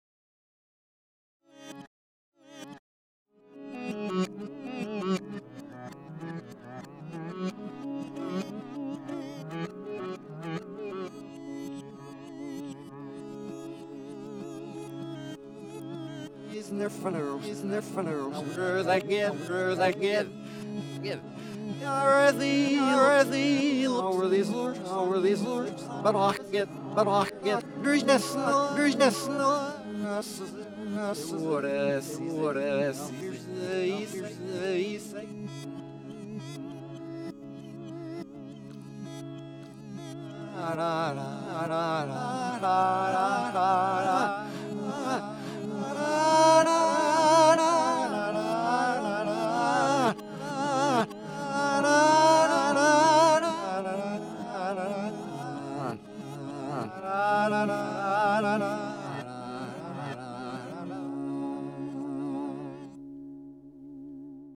"A Flask of Spanish Perfume" (This is a short excerpt from a nearly endless, and pointless, British folk ballad parody.)
Reverse Repeat [mp3] — Processed through Backwards Machine in Reverse Repeat mode
All examples use the default parameters (no feedback, no dry mix).